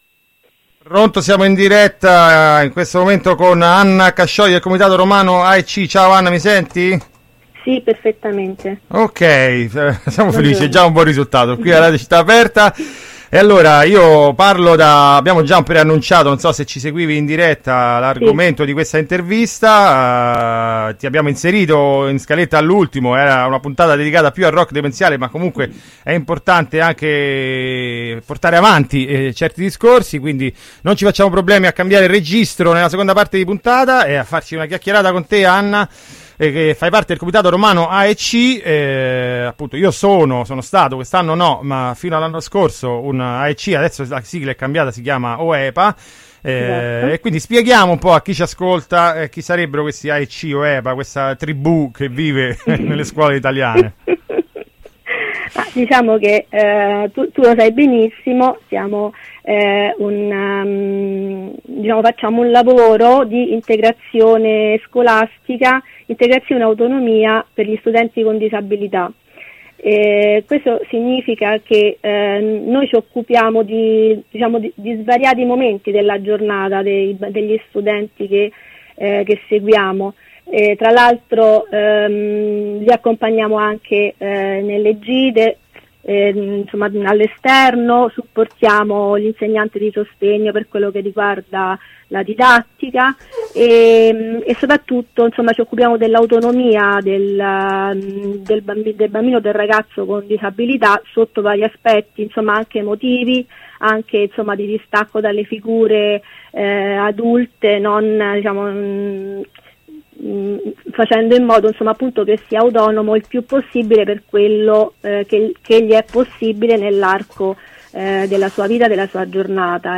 Intervento telefonico per parlare a proposito dell’approvazione della delibera comunale per l’assunzione dei primi 300 operatori educativi OEPA (ex AEC) da parte del comune di Roma.